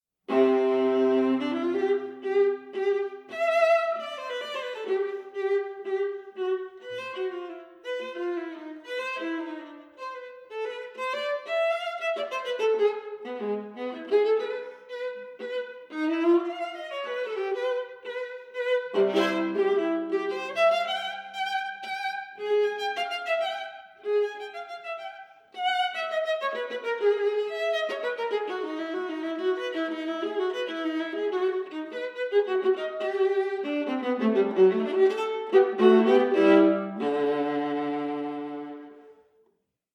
Stereo
for solo viola